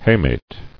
[ha·mate]